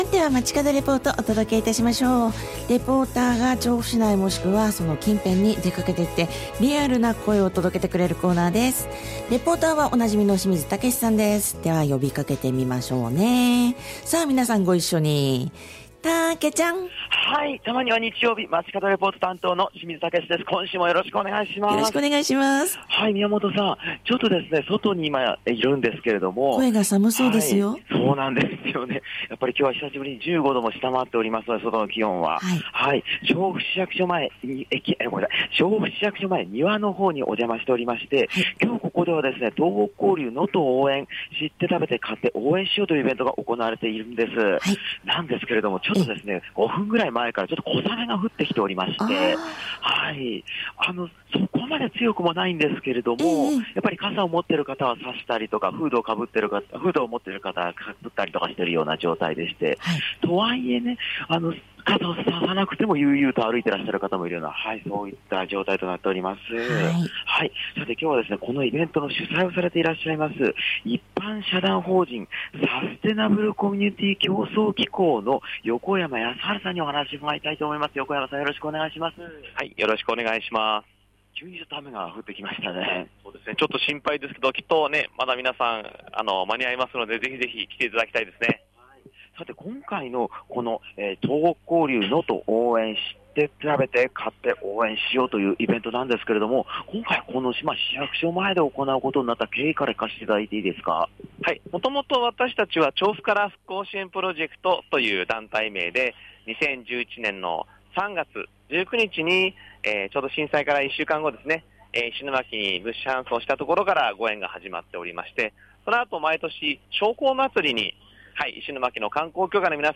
小雨もぱらつく下からお届けした本日の街角レポートは、
調布市役所前庭で開催されている「東北交流・能登応援 防災イベント」の会場からお届けしました！